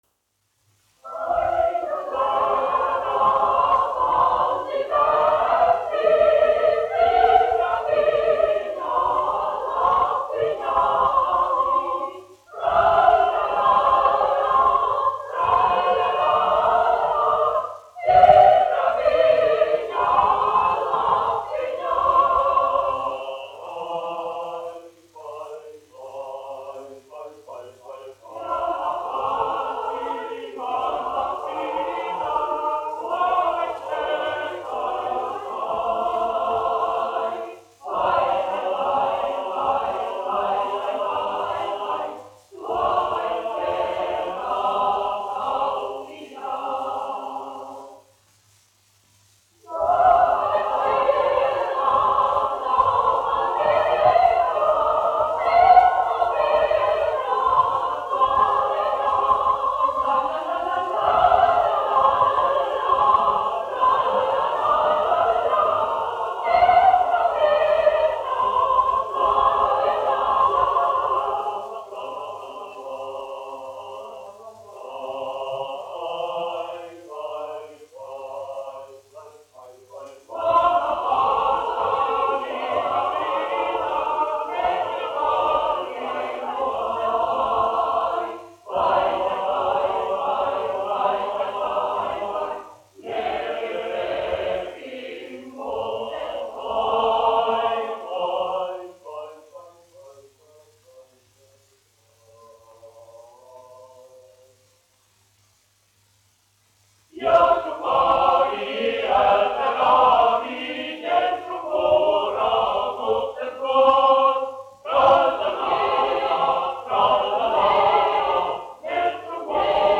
Reitera koris, izpildītājs
Teodors Reiters, 1884-1956, diriģents
1 skpl. : analogs, 78 apgr/min, mono ; 25 cm
Latgaliešu tautasdziesmas
Kori (jauktie)
Latviešu tautasdziesmas